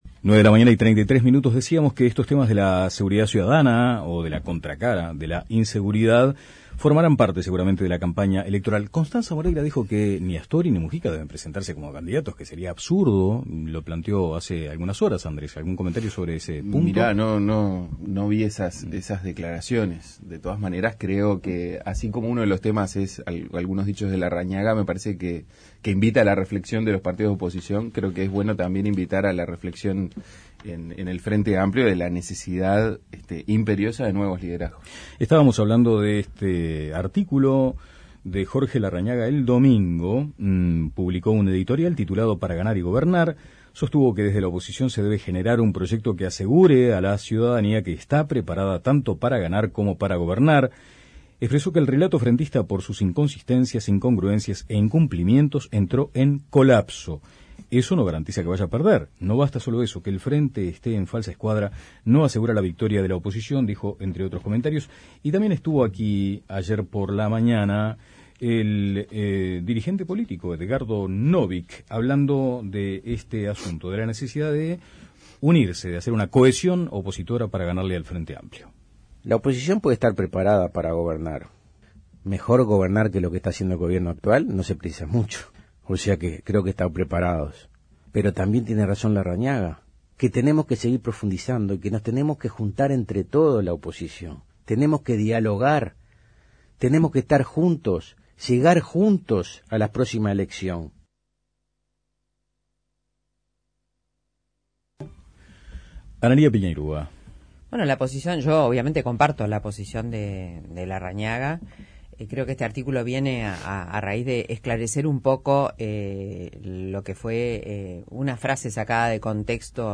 Sobre estos dichos comenzó la discusión en esta mesa de lunes.